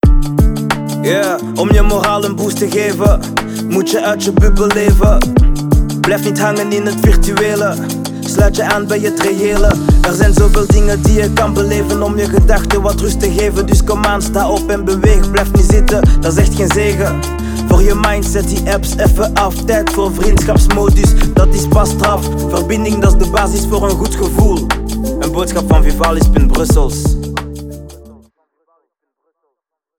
Des spots audio en format Slam seront diffusés sur les comptes gratuits de Spotify.